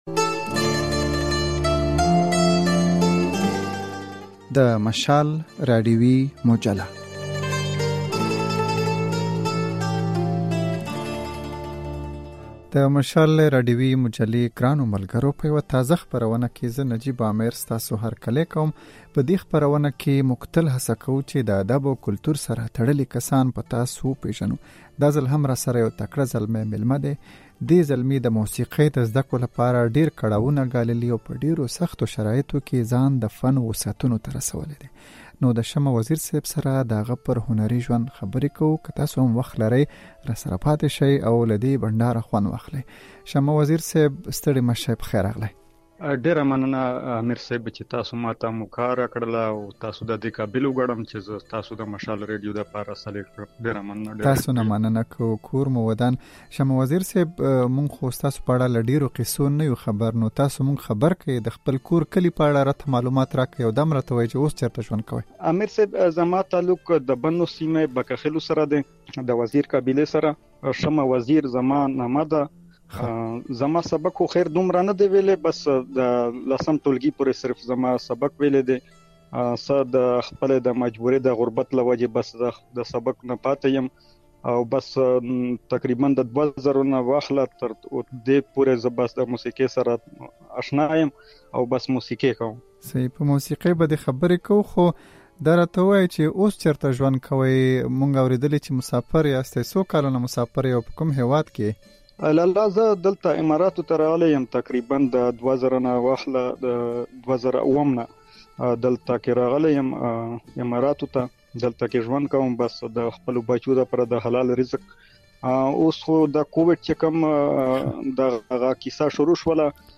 مشال راډیويي مجله